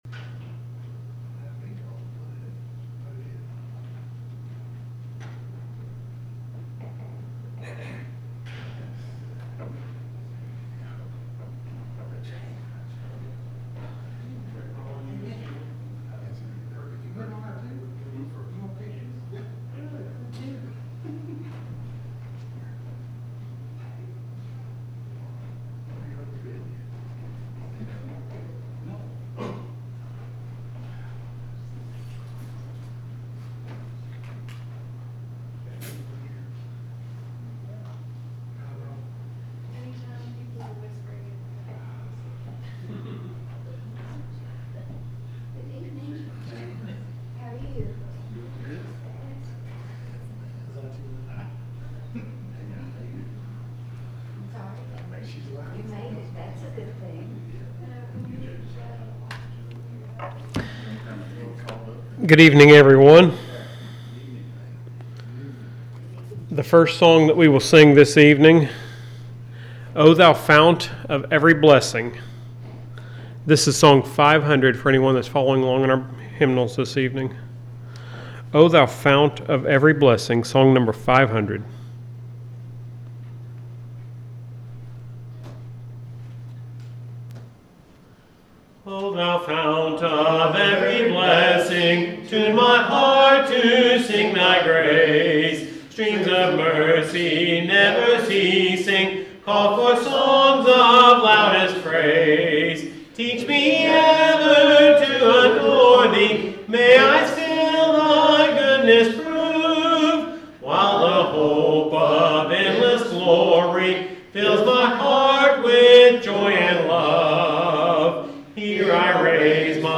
The sermon is from our live stream on 7/23/2025